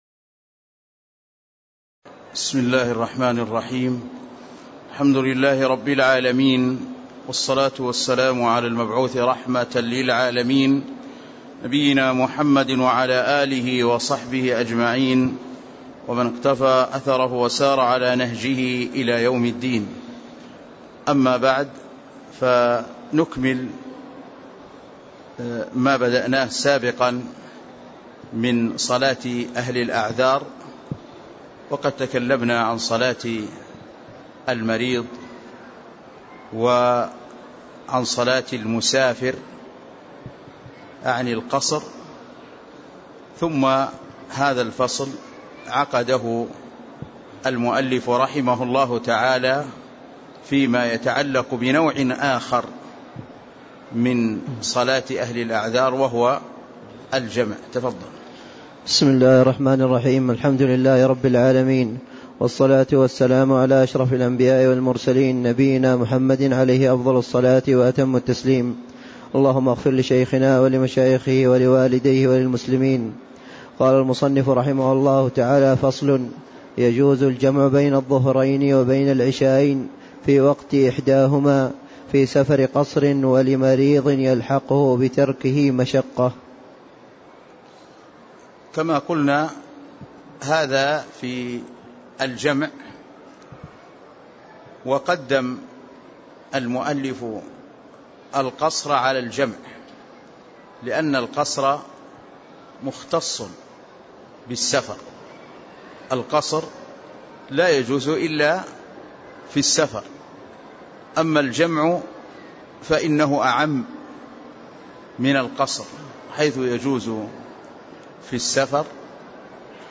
تاريخ النشر ٢٤ صفر ١٤٣٦ هـ المكان: المسجد النبوي الشيخ